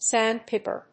音節sánd・pìper 発音記号・読み方
/ˈsænd.paɪpɜ(米国英語)/